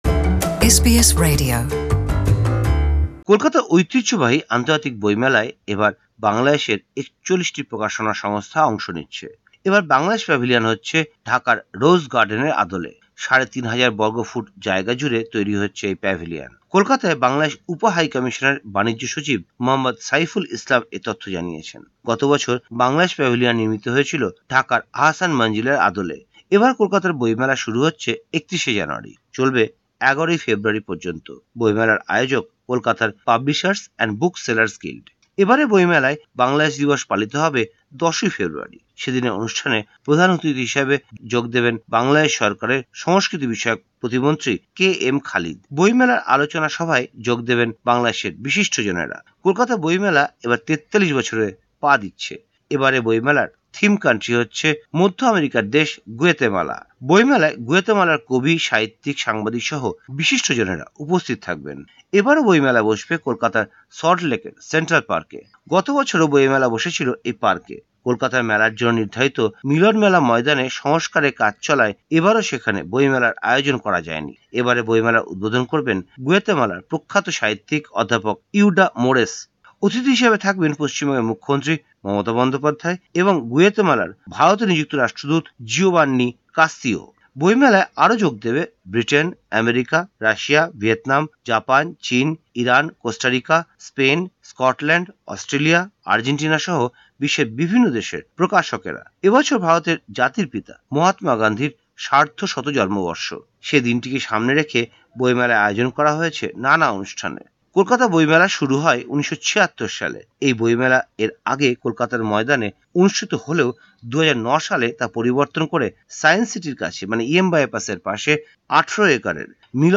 প্রতিবেদনটি বাংলায় শুনতে উপরের অডিও প্লেয়ারটিতে ক্লিক করুন।